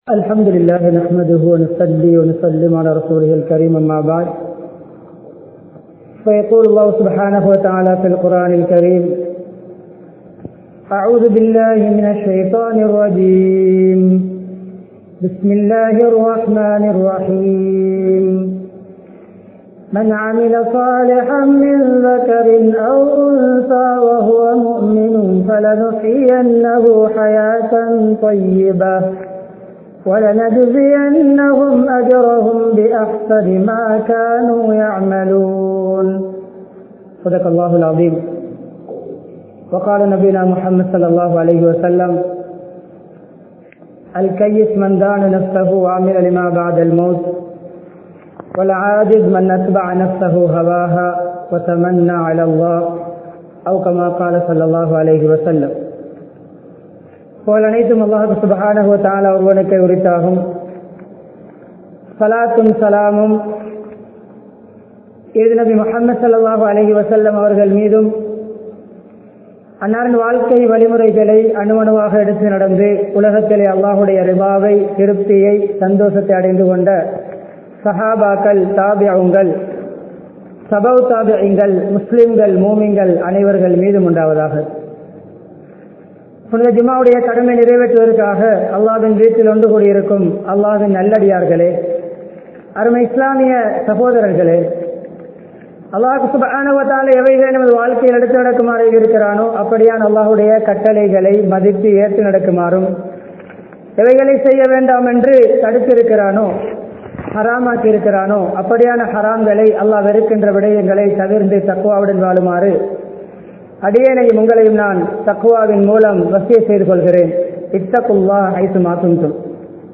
Nimmathiyaana Vaalkai (நிம்மதியான வாழ்க்கை) | Audio Bayans | All Ceylon Muslim Youth Community | Addalaichenai
Matala, Ukuwela, Maberiya Jumua Masjidh